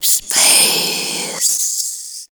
WHISPER 09.wav